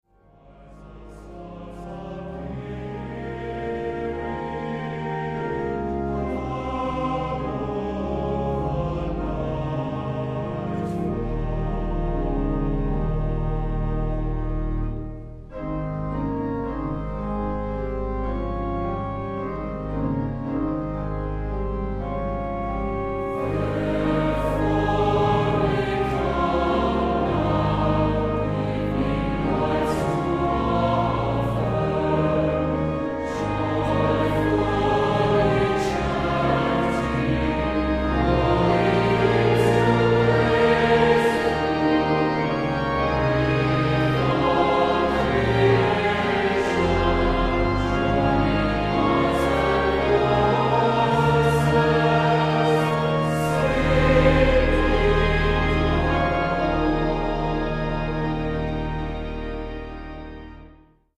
Voicing: Assembly